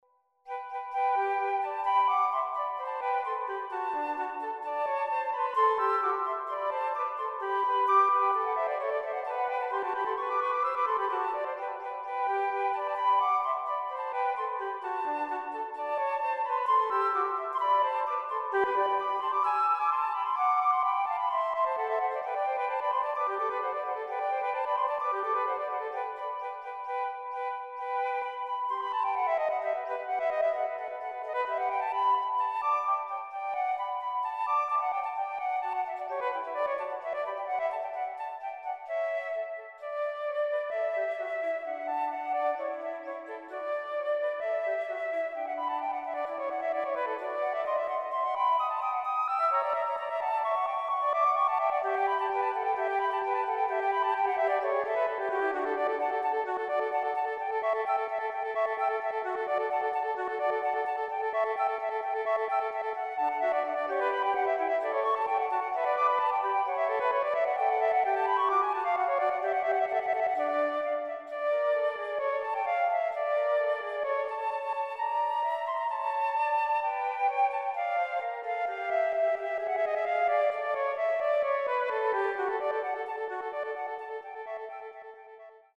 konzertanten Duos
Flötisten